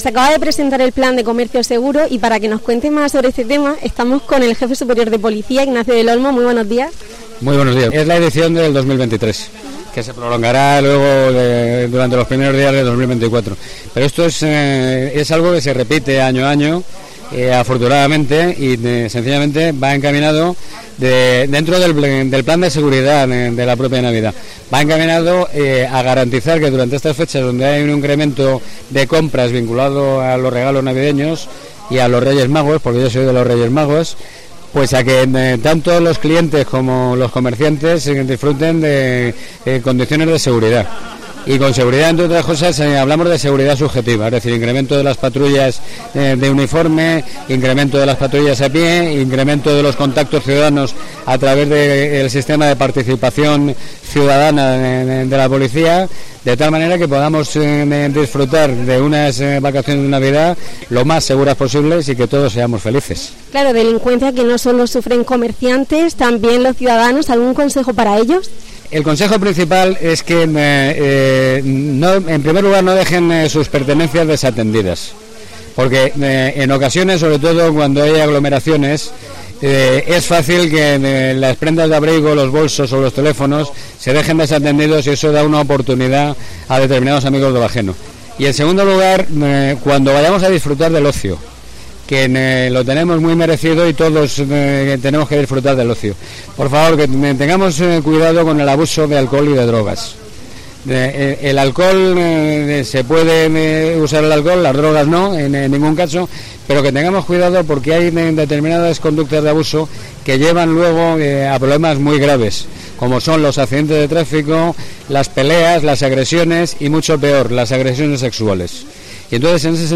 Ignacio del Olmo, Jefe Superior de Policía de la Región de Murcia
Escucha aquí a Ignacio del Olmo